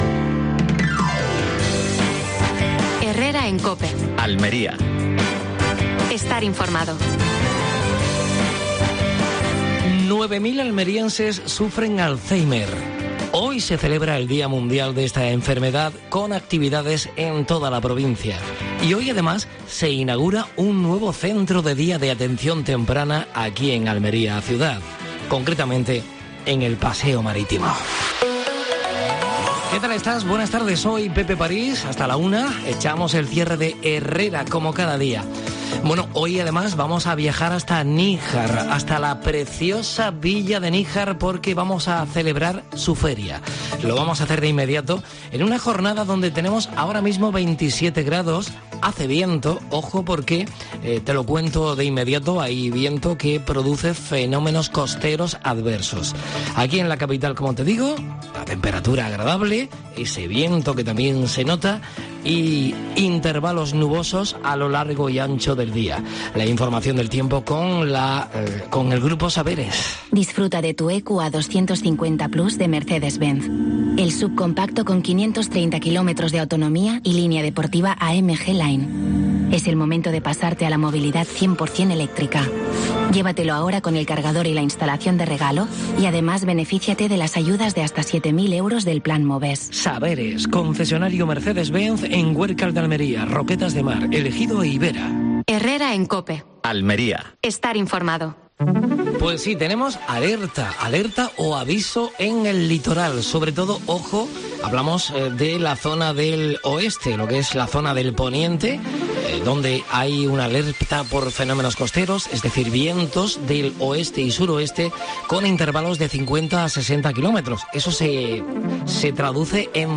AUDIO: Programa especial con motivo de la Feria de Níjar. Entrevista